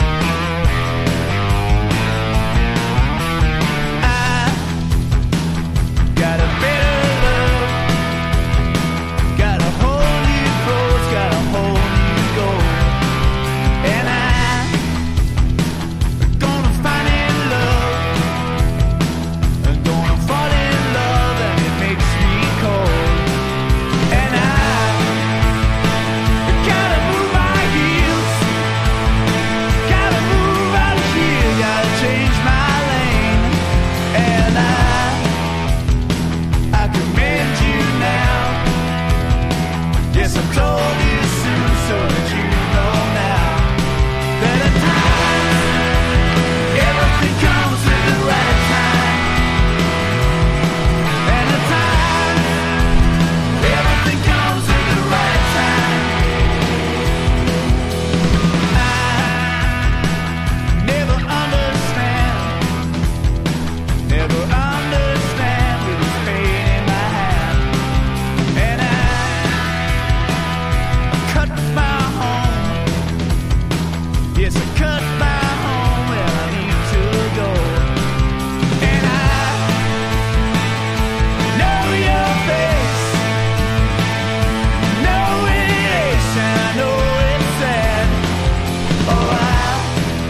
1. 90'S ROCK >